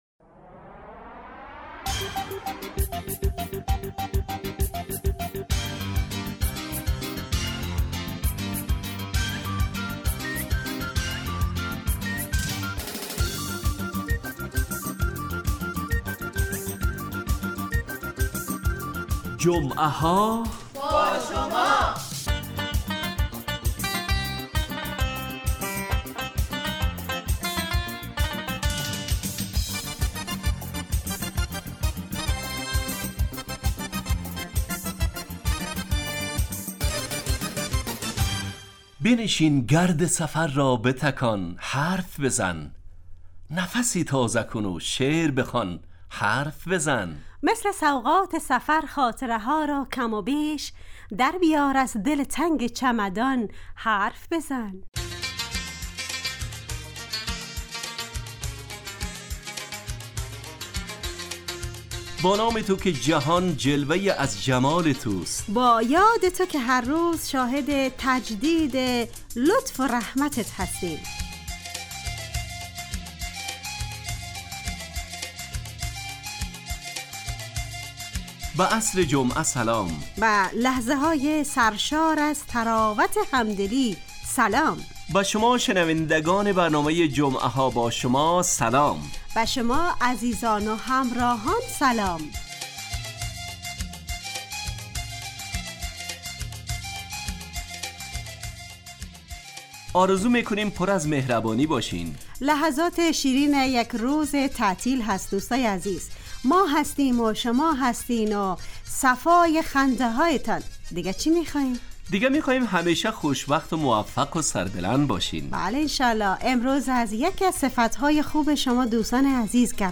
جمعه ها باشما برنامه ایست ترکیبی نمایشی که عصرهای جمعه بمدت 40 دقیقه در ساعت 17:15 دقیقه به وقت افغانستان پخش می شود و هرهفته به یکی از موضوعات اجتماعی...